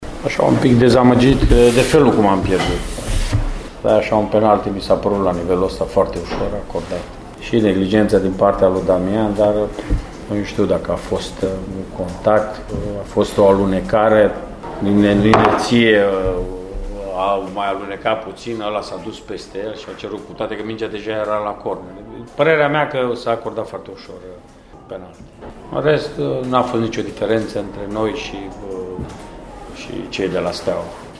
Antrenorul gazdelor, Mircea Rednic, s-a declarat dezamăgit de acordarea loviturii de pedeapsă, ținând cont de conjunctura în care s-a produs faza din careul arădean: